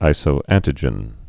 (īsō-ăntĭ-jən)